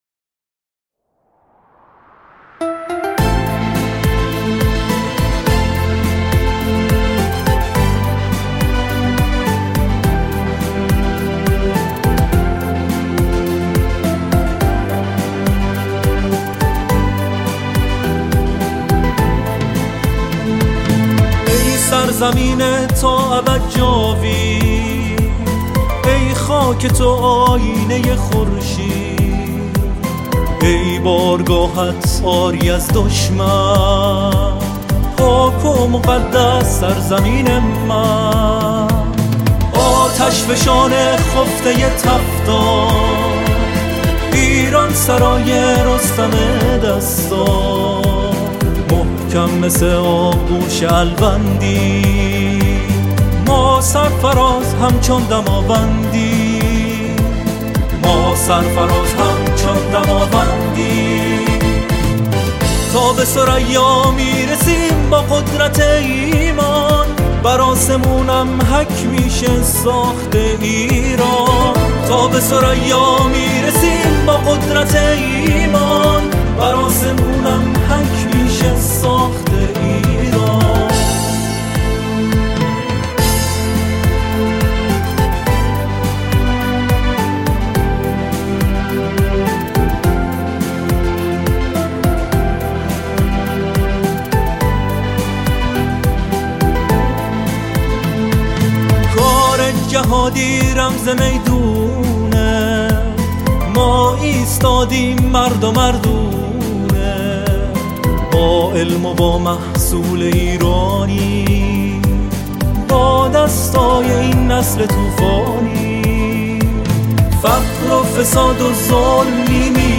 خواننده آواهای دینی